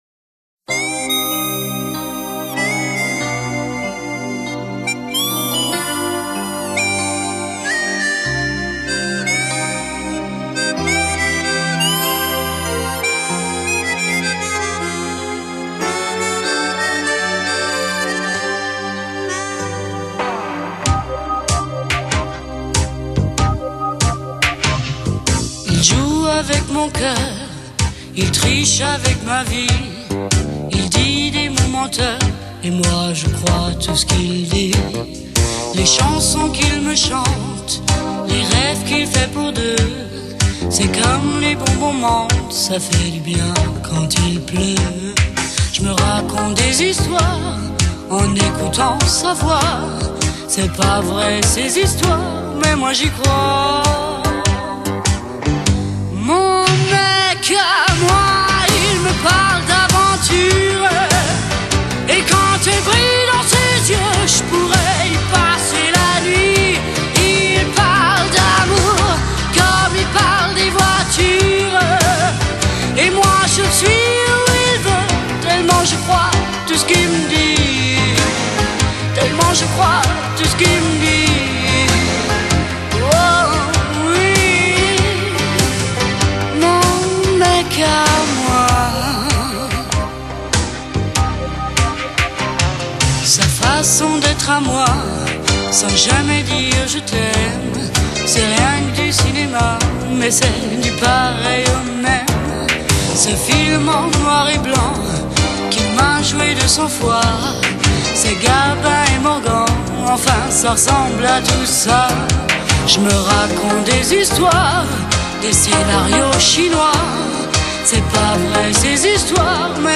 Genre: Chanson, Jazz, Pop, Jazz Rock